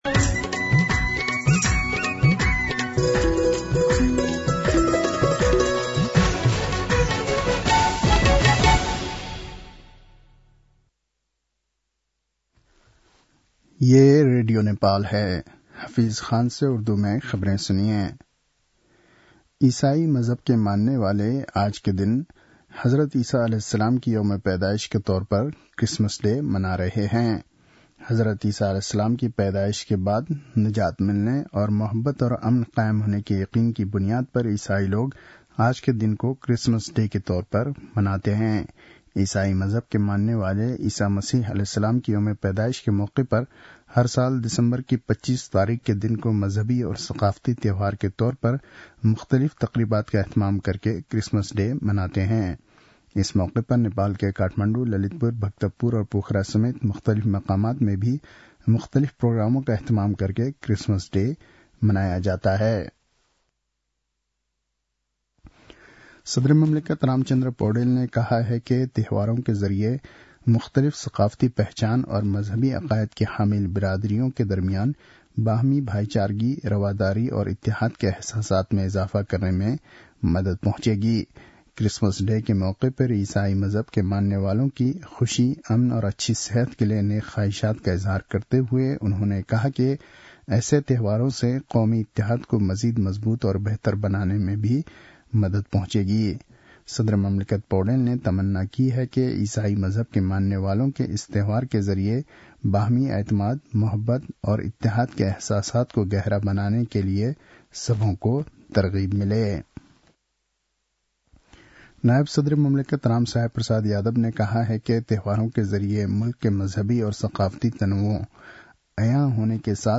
उर्दु भाषामा समाचार : ११ पुष , २०८१
Urdu-News-9-10.mp3